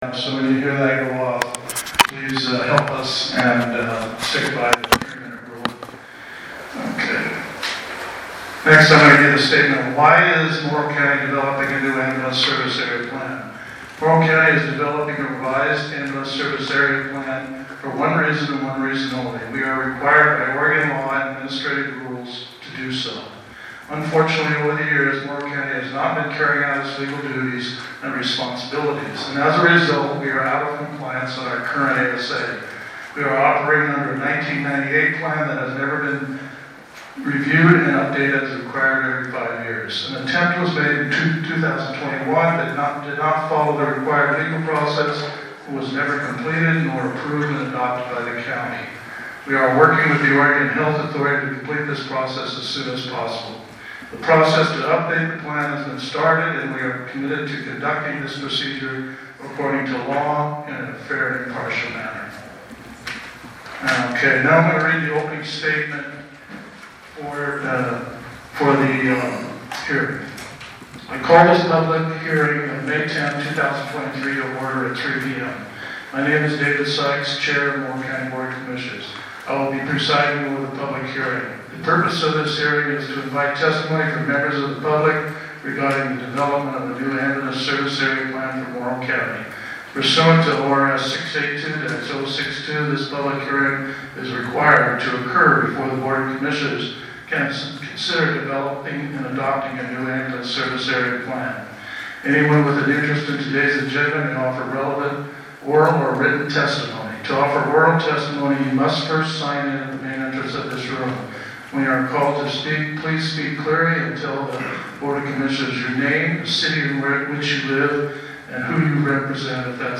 Board of Commissioners Public Hearing - Boardman | Morrow County Oregon